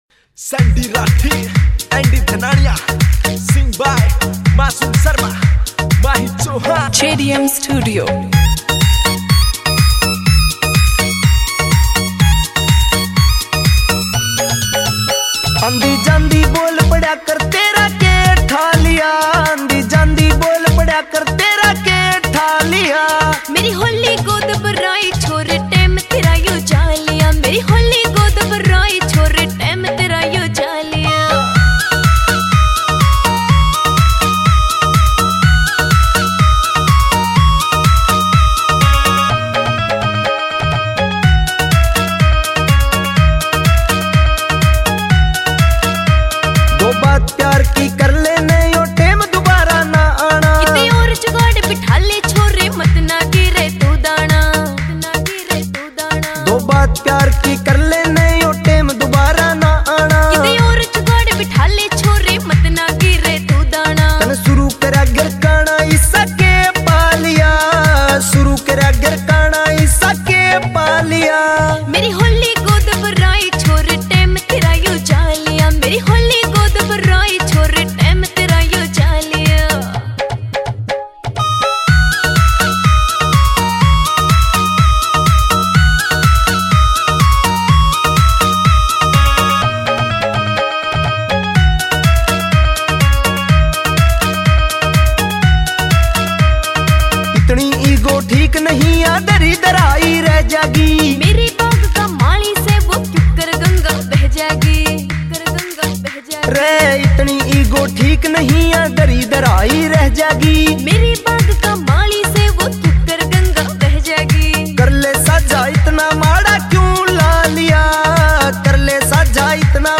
[ Haryanvi Songs ]